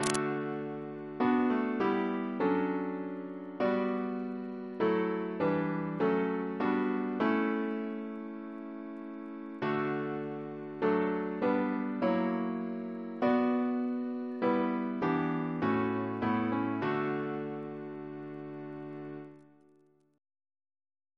Double chant in D Composer: Chris Biemesderfer (b.1958) Note: related to d minor chant, paired for Psalm 139